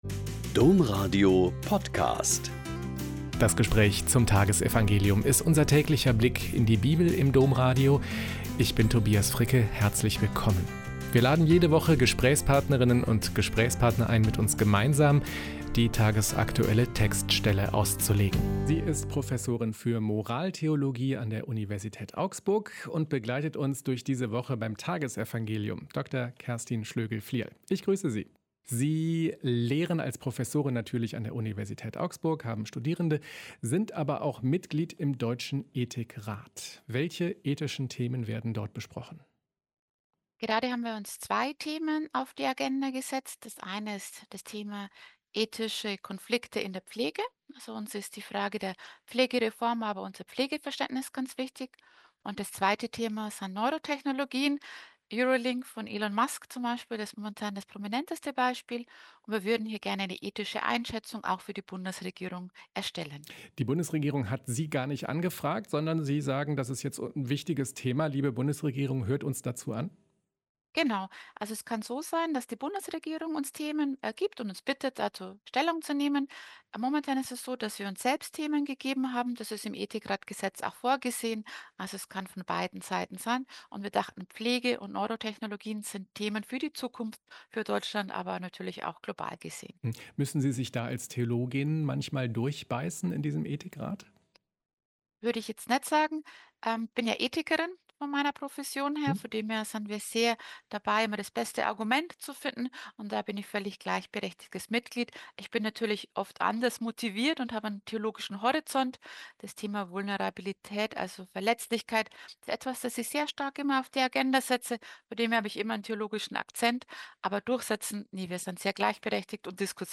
Mt 9,18-26 - Gespräch